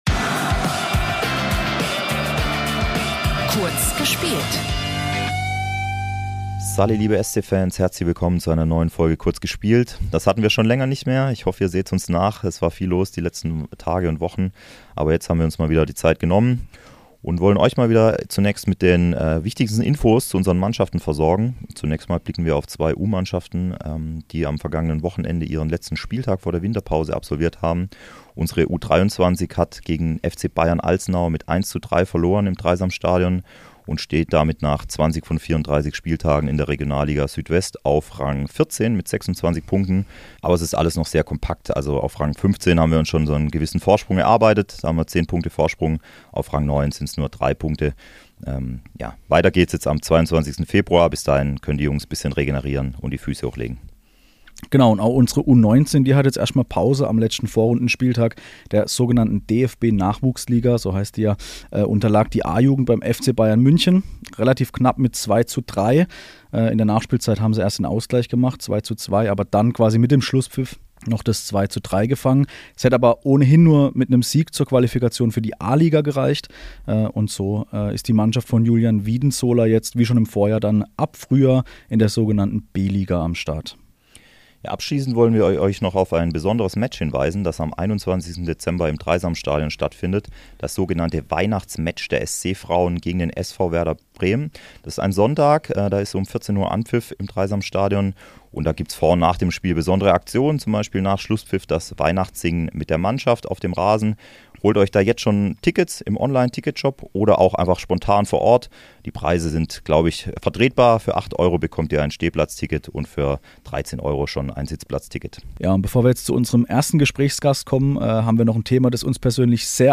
Beschreibung vor 4 Monaten Im Podcast-Kurzinterview spricht Philipp Lienhart nach seinem 200. Bundesligaspiel über sein Jubiläum, das Europa-League-Spiel gegen RB Salzburg und die österreichische WM-Gruppe. In der neuen Folge „Kurz gespielt“ kommt außerdem Andreas Ibertsberger zu Wort, der sowohl für den Sport-Club als auch für Salzburg gespielt hat.